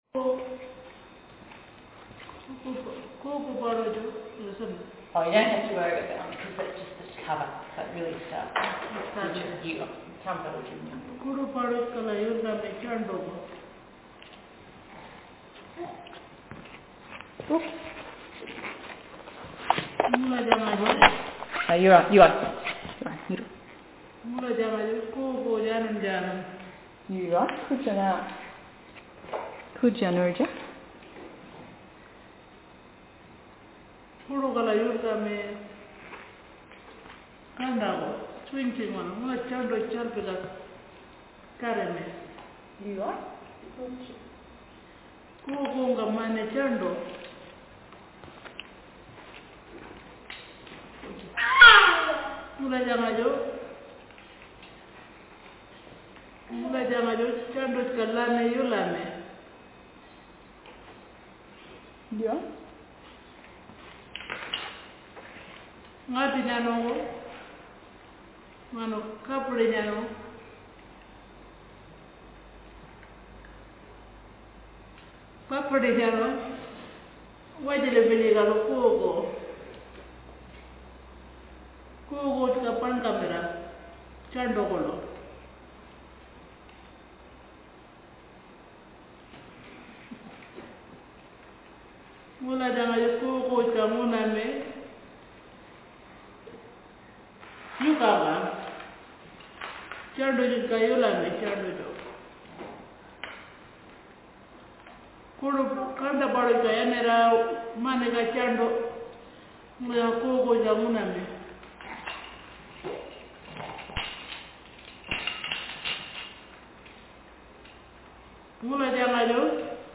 Speaker sexf
Text genrestimulus retelling